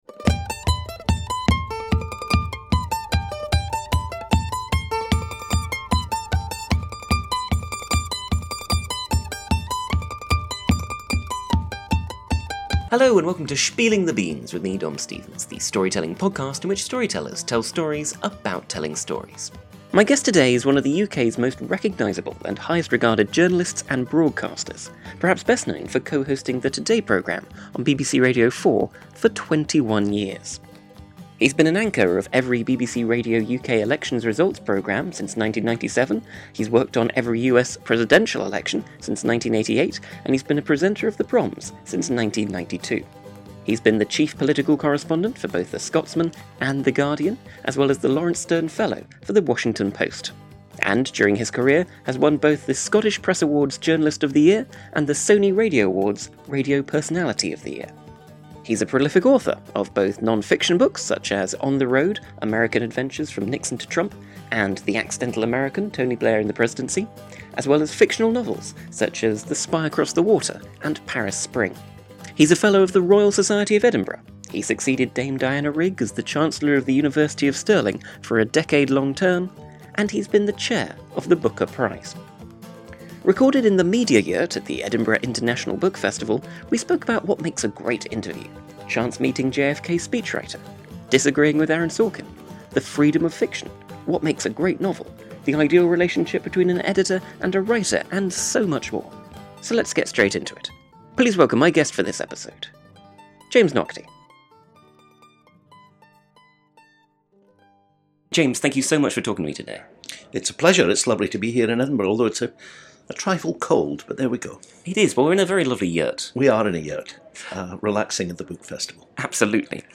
Recorded in the Media Yurt at - and with special thanks to - the Edinburgh International Book Festival, August 2023.